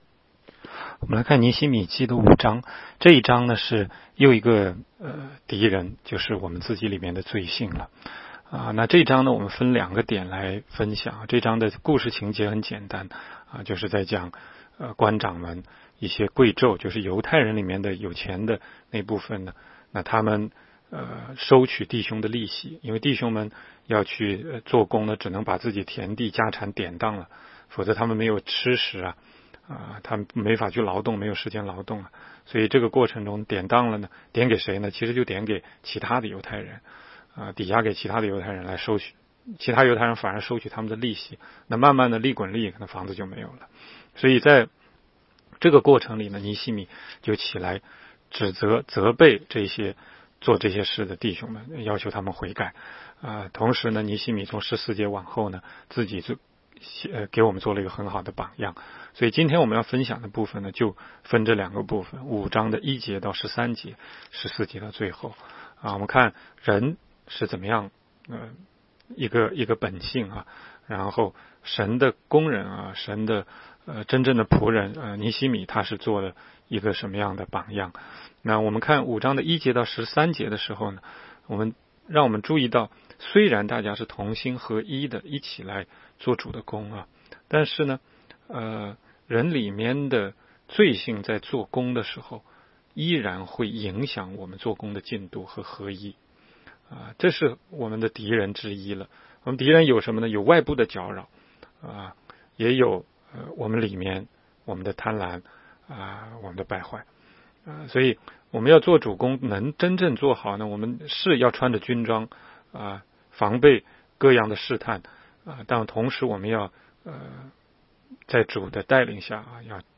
16街讲道录音 - 每日读经-《尼希米记》5章